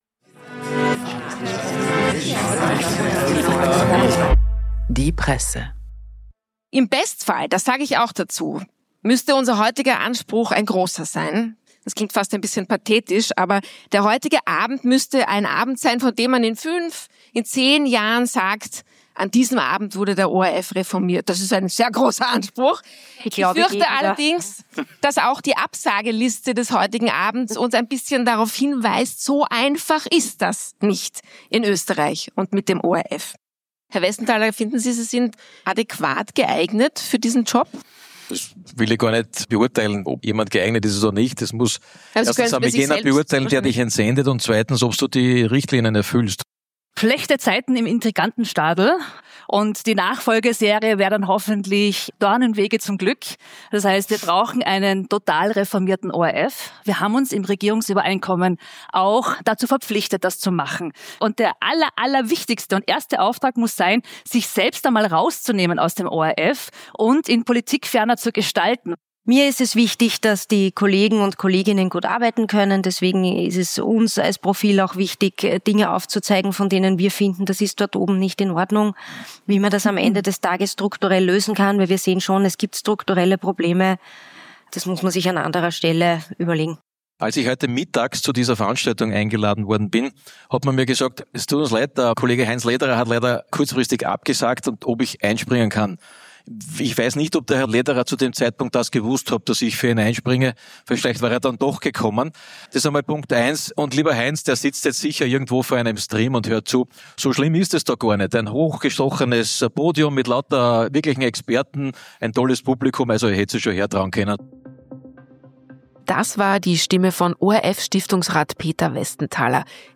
Die Live-Diskussion von „Presse“, „Kleine Zeitung“, „Kurier“ und „Profil“ zum Nachhören.